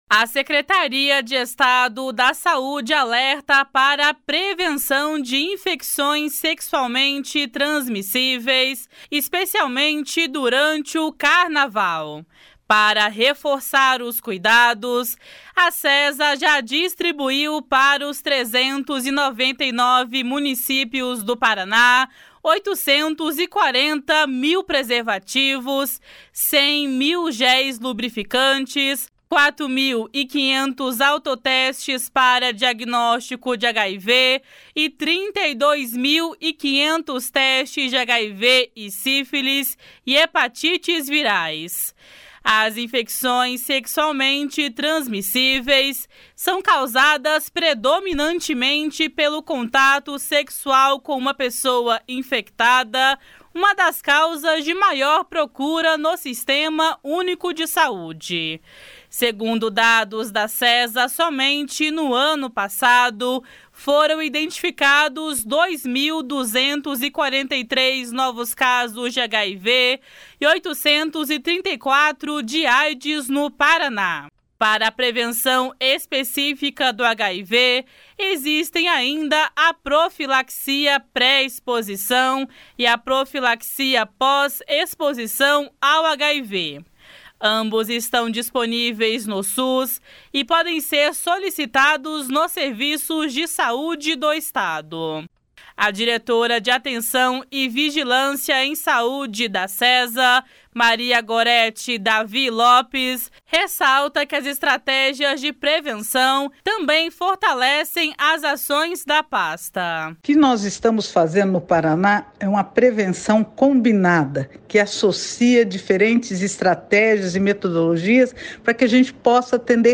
Por isso, a recomendação é a utilização de preservativos e a vacinação. (Repórter: